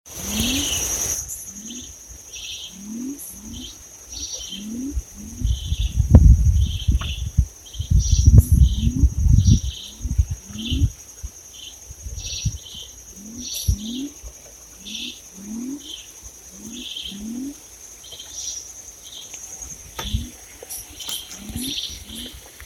Rana Criolla (Leptodactylus luctator)
080122-AVE-Chivi-y-Rana-Criolla_10_25.mp3
Fase de la vida: Adulto
Localización detallada: Eco Área Avellaneda
Condición: Silvestre
Certeza: Vocalización Grabada